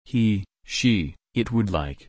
/laɪk/
/laɪkt/